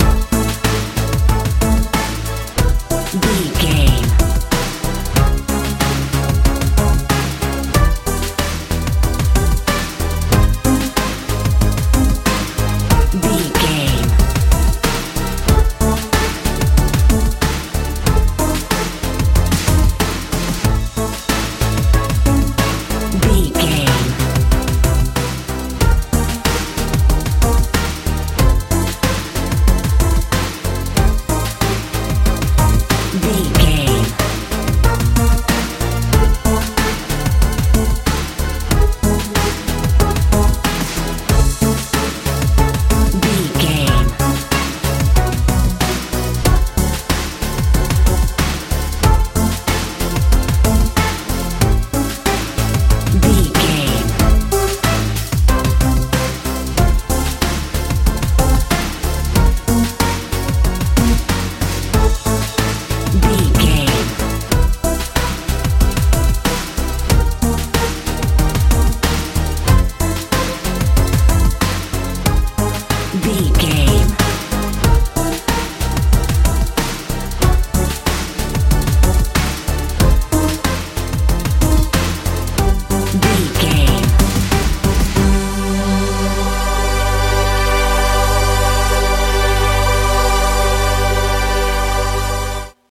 modern dance feel
Ionian/Major
strange
hypnotic
suspense
searching